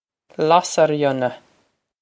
Lasairfhiona Lass-uh-ree-un-uh
Pronunciation for how to say
This is an approximate phonetic pronunciation of the phrase.